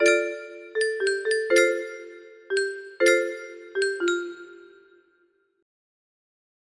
AlwaysPRVW music box melody